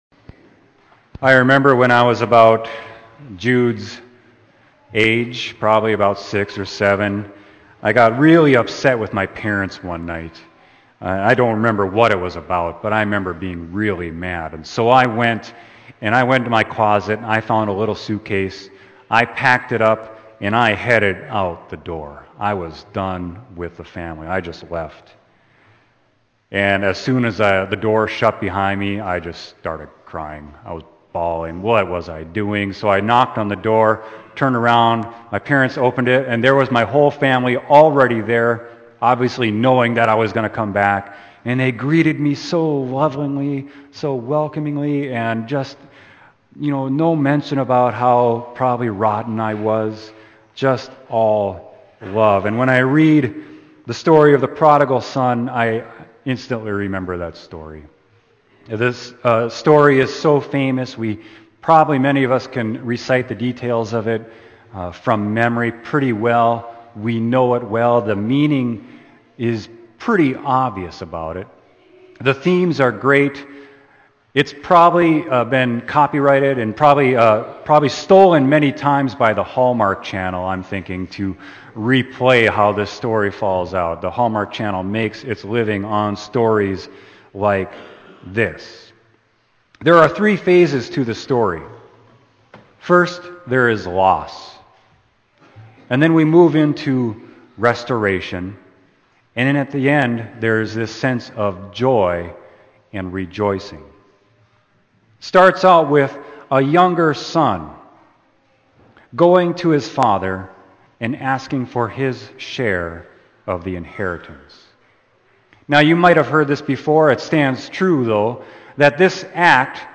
Sermon: Luke 15.11-24